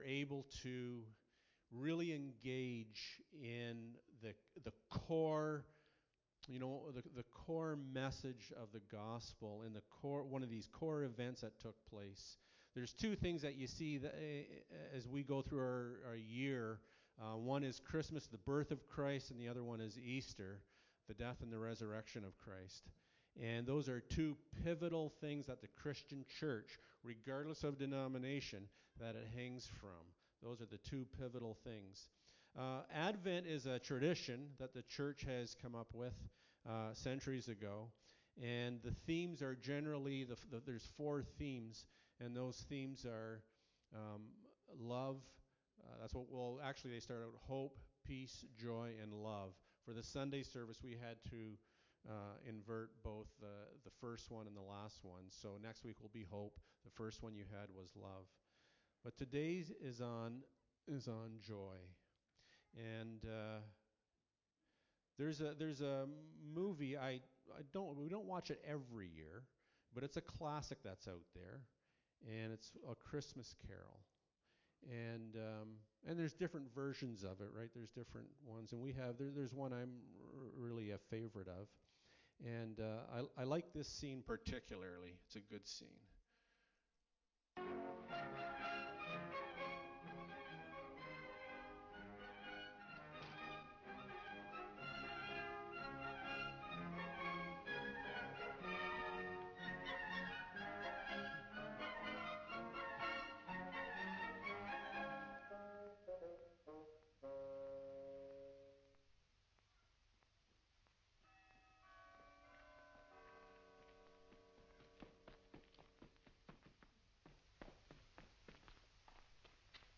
Luke 2:8-14 Service Type: Sunday Morning Bible Text